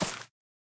Minecraft Version Minecraft Version snapshot Latest Release | Latest Snapshot snapshot / assets / minecraft / sounds / block / vine / break1.ogg Compare With Compare With Latest Release | Latest Snapshot